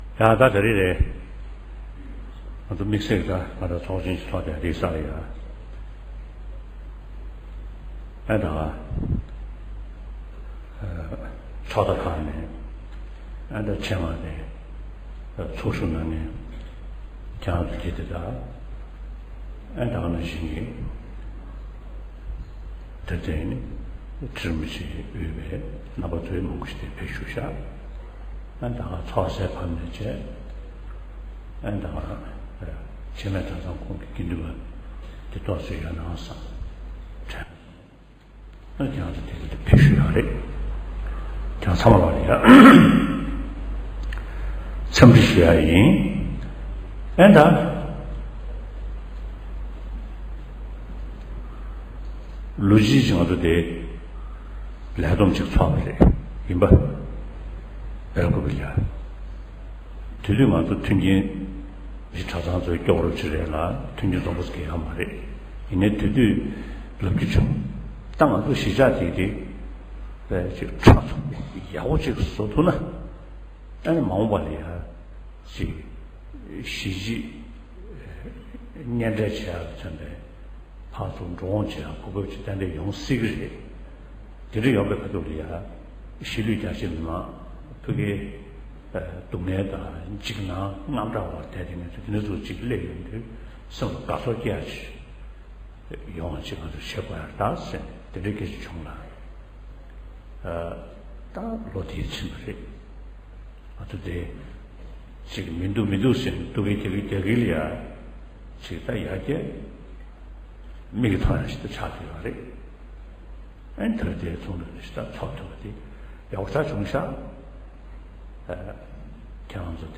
བོད་གཞུང་མང་རྒྱལ་ཡོངས་ཚོགས་ཆེན་གྱི་མཇུག་སྒྲིལ་མཛད་སྒོའི་སྐབས་༸གོང་ས་མཆོག་ནས་བཀའ་སློབ་བསྩལ་བ།